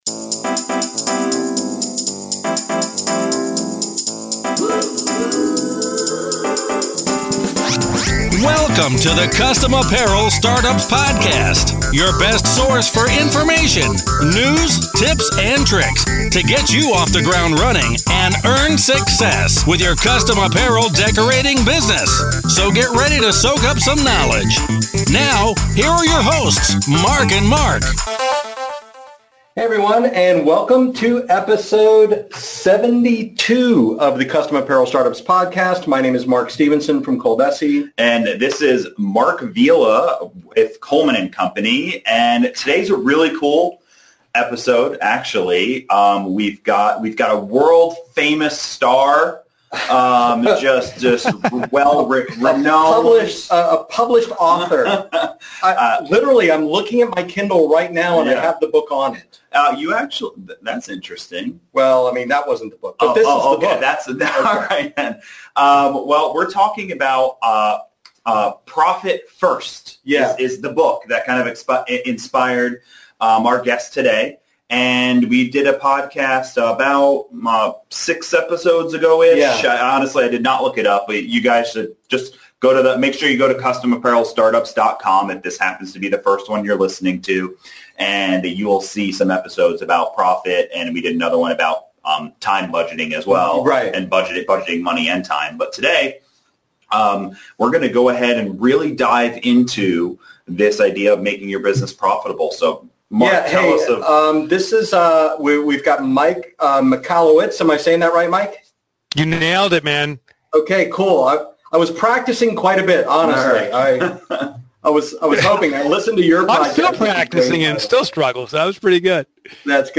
Episode 72: Profit FIRST! Interview With Mike Michalowicz